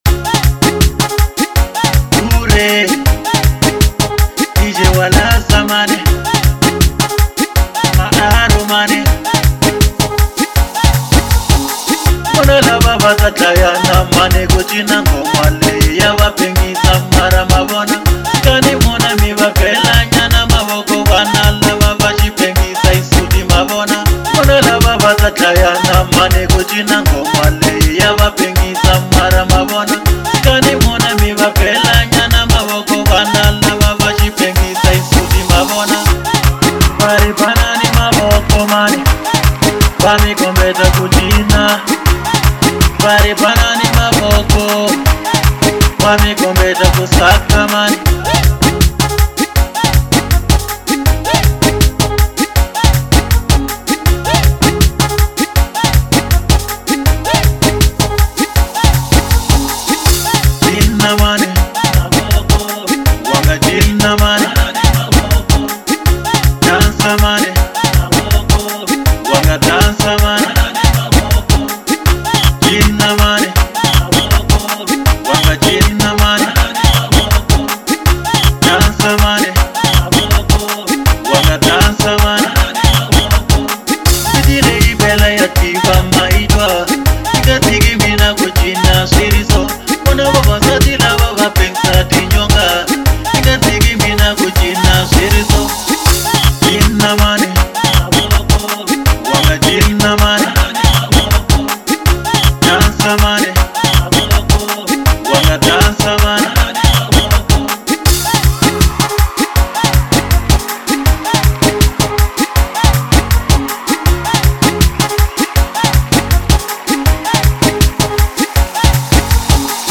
04:21 Genre : Xitsonga Size